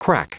Prononciation du mot crack en anglais (fichier audio)
Prononciation du mot : crack